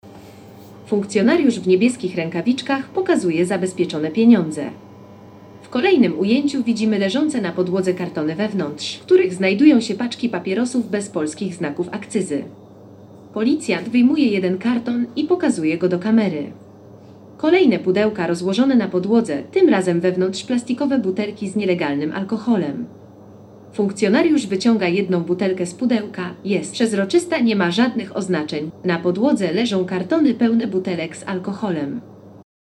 Nagranie audio Audiodeskrypcja_Kontrabanda.mp3